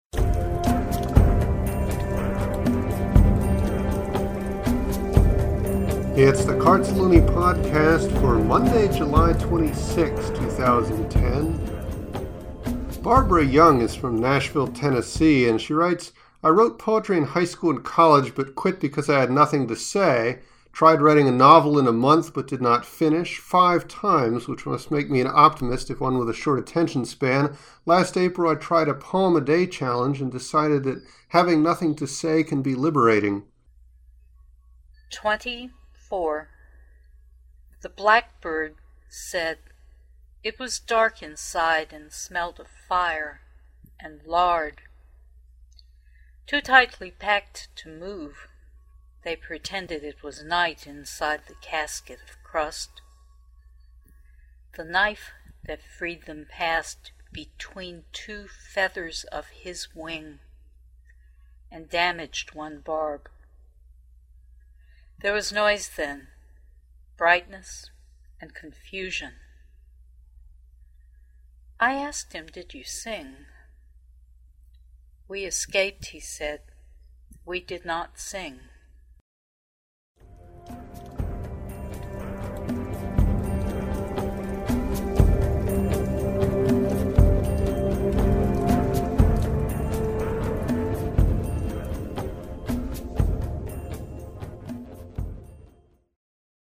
It’s great to hear your poetry in your voice.